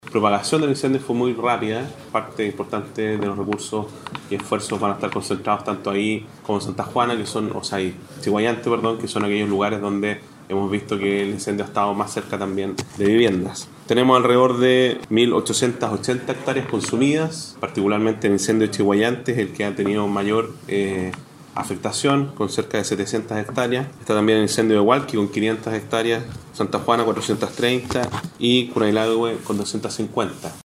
Al respecto, el intendente Sergio Giacaman, se refirió a la afectación que han dejado estos incendios. De acuerdo a esta última actualización, los incendios han afectado a 430 hectáreas en Santa Juana; 500 en Hualqui y 800 en Chiguayante.